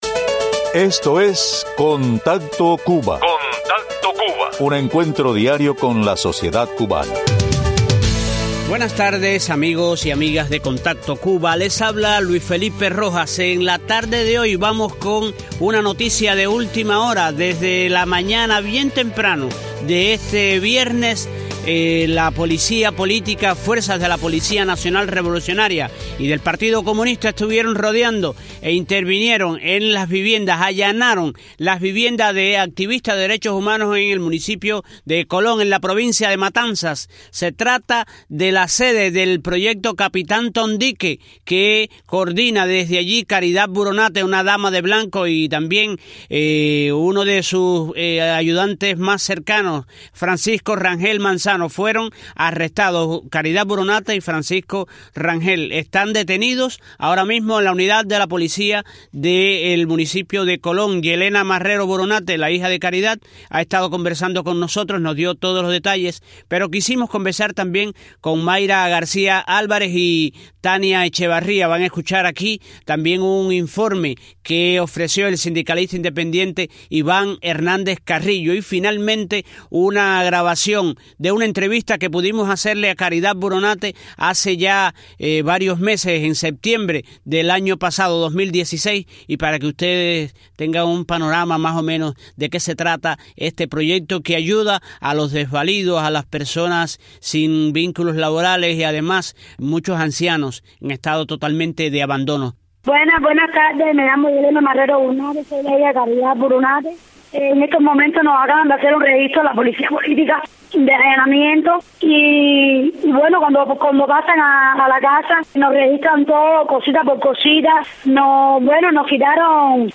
entrevista de archivo